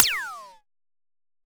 laser5.wav